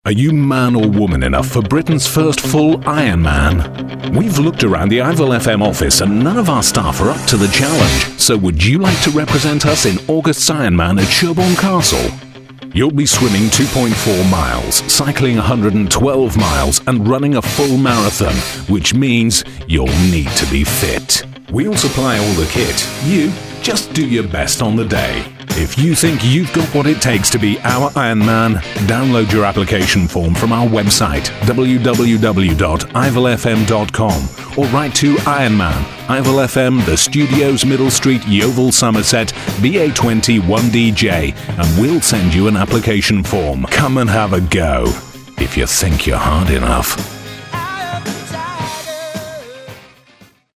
Sprecher englisch (uk, british english). Radio / TV Stimme.
britisch
Sprechprobe: eLearning (Muttersprache):
english voice over artist (uk, british english).